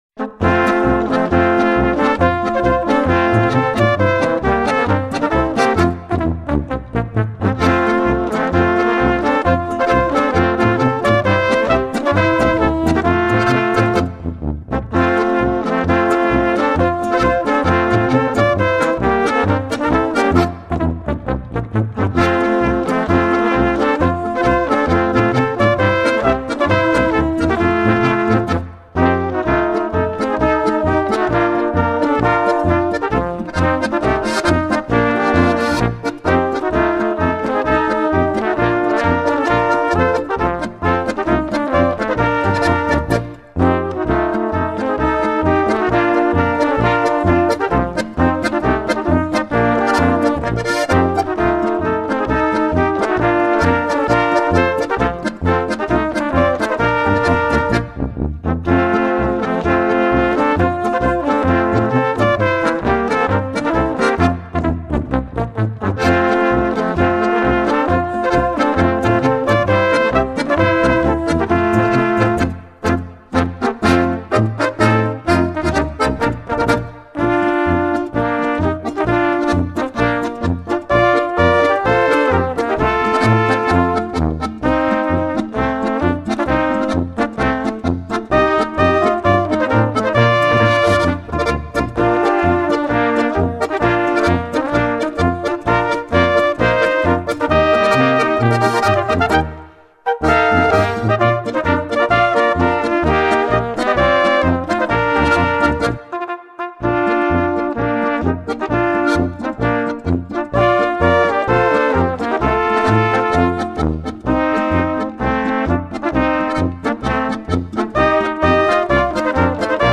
Unterkategorie Gemischte folkloristische Besetzung
Besetzung Tanzlmusi
Komponist Traditionell